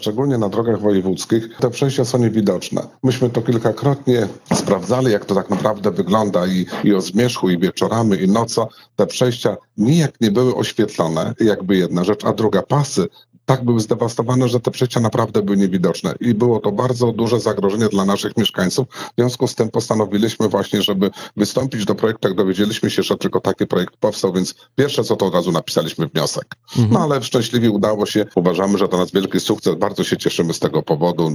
Wójt gminy Dariusz Rafalik mówi, że taka inwestycja z pewnością poprawi bezpieczeństwo zarówno pieszych mieszkańców jak i kierowców.